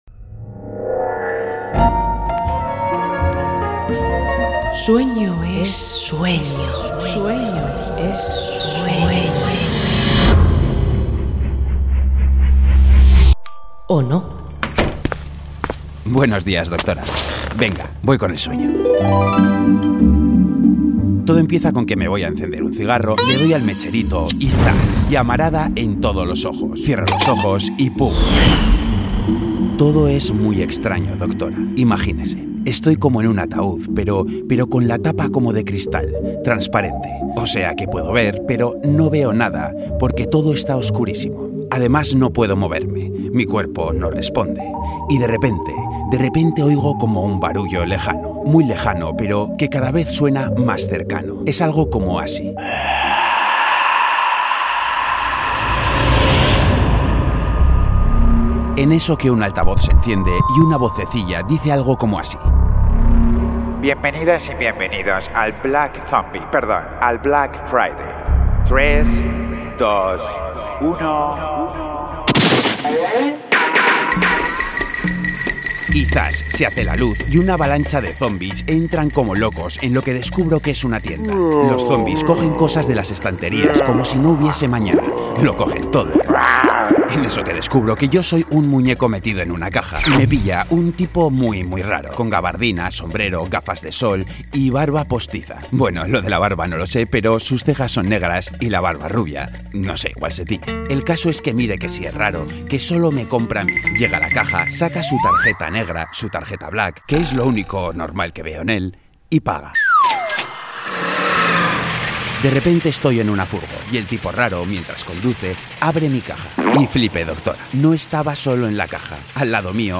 Ficción Sonora en Boulevard.